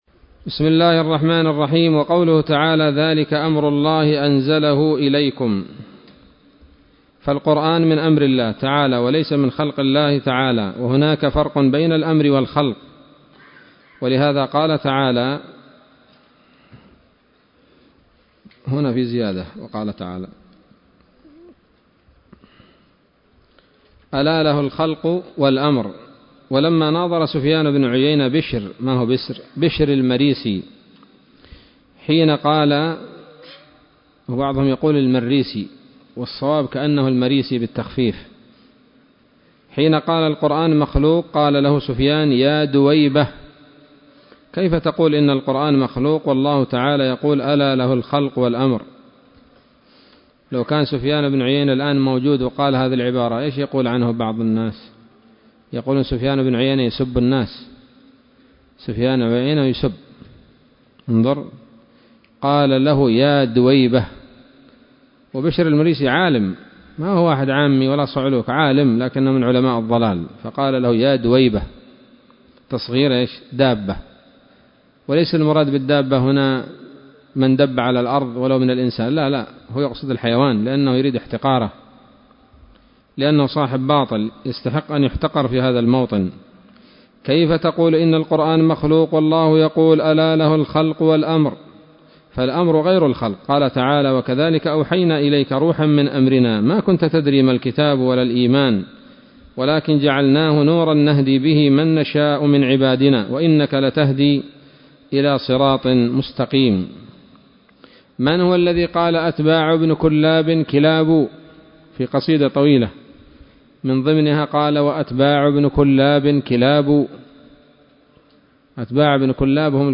الدرس التاسع والستون